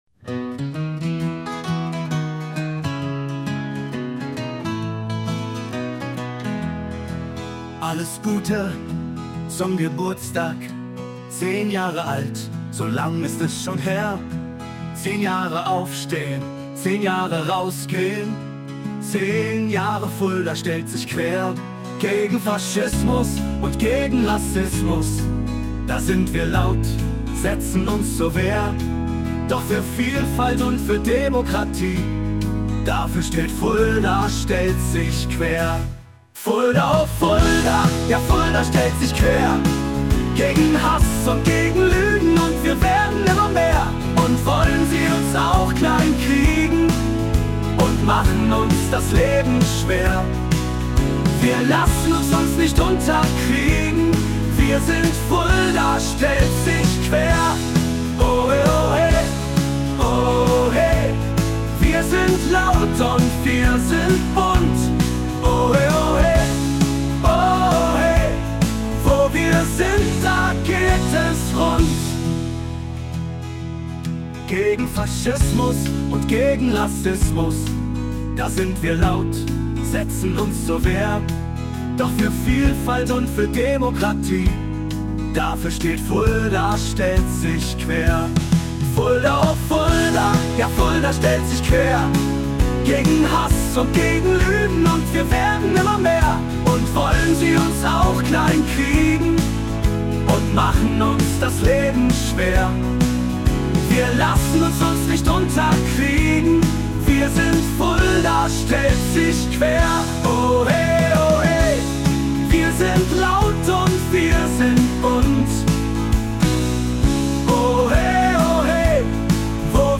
audio_pop.mp3